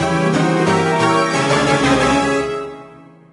wave_boss_fight_01.ogg